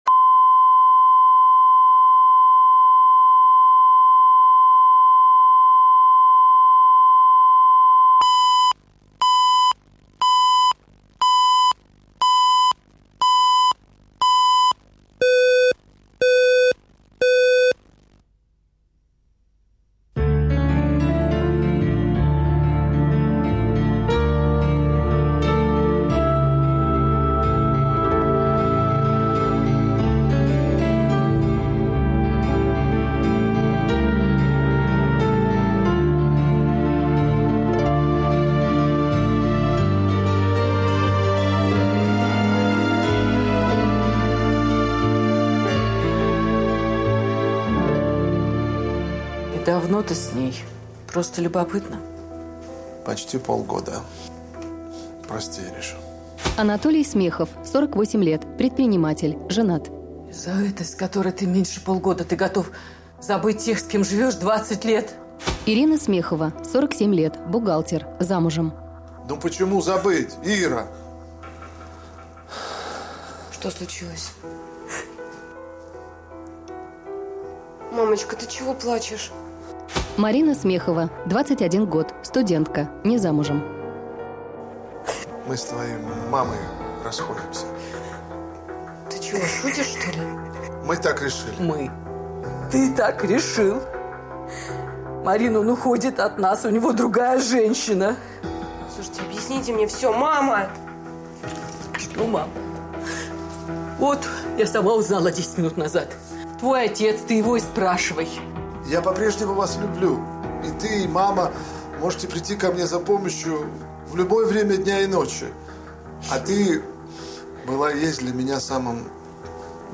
Аудиокнига Как папа | Библиотека аудиокниг